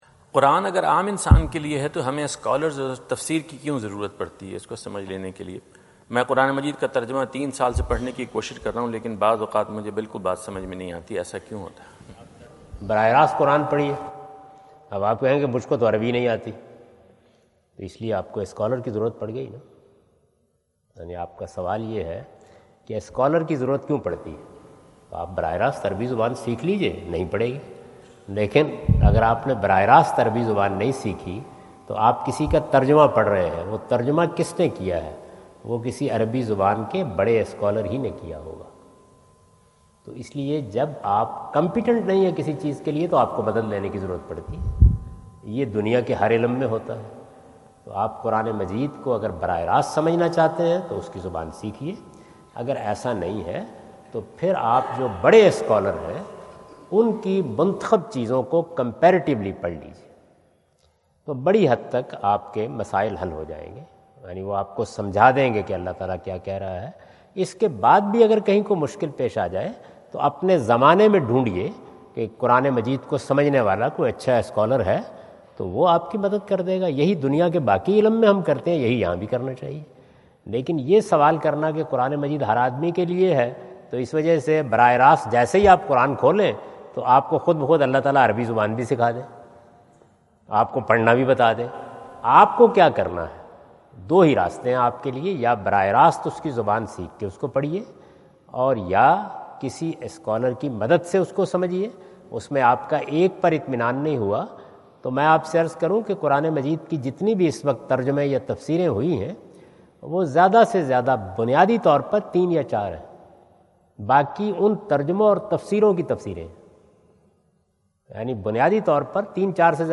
Javed Ahmad Ghamidi answer the question about "If Quran addresses common person then why do we need scholars and Tafseer?" During his US visit at Wentz Concert Hall, Chicago on September 23,2017.